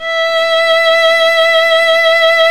Index of /90_sSampleCDs/Roland - String Master Series/STR_Violin 2&3vb/STR_Vln2 mf vb